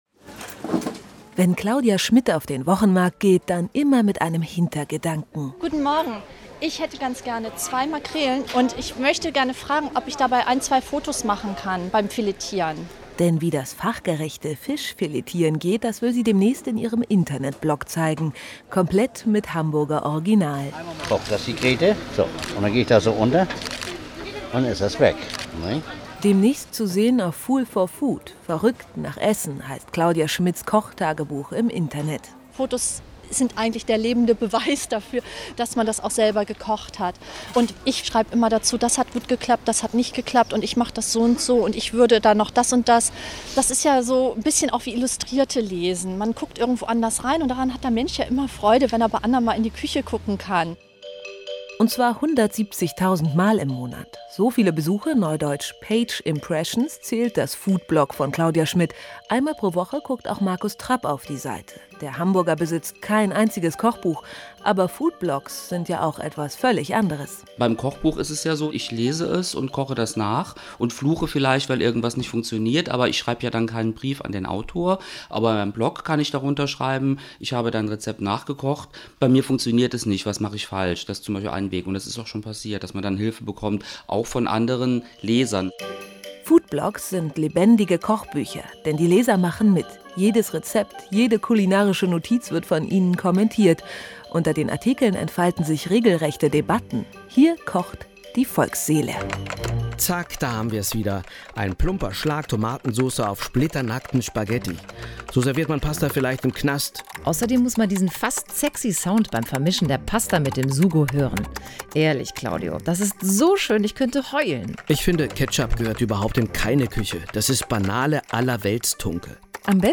Interview und Marktbesuch
Und für alle, die jetzt gespannt sind auf den Radiobeitrag auf NDR-Kultur: